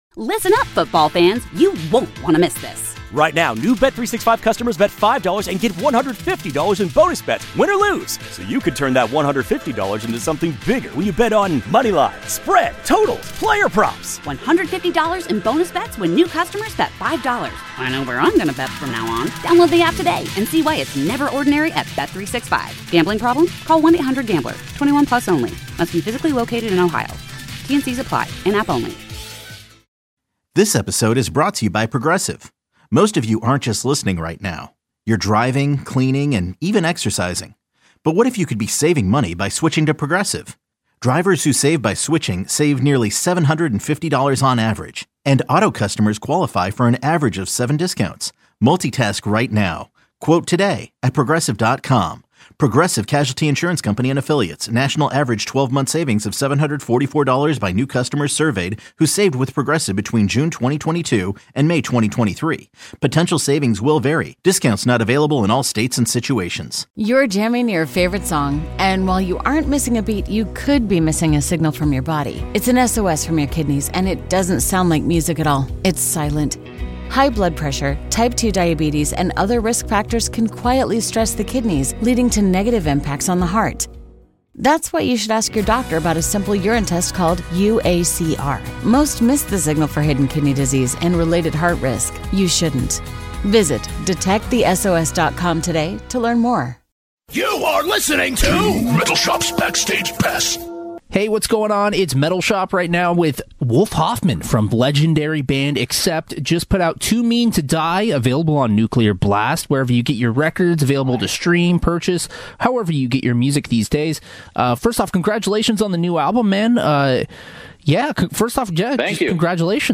Wolf Hoffmann is the last remaining member of the O.G. Accept lineup and it doesn’t sound like he’s going anywhere. I spoke with Wolf about the new Accept album “Too Mean to Die”, life during the pandemic, early inspirations and much more.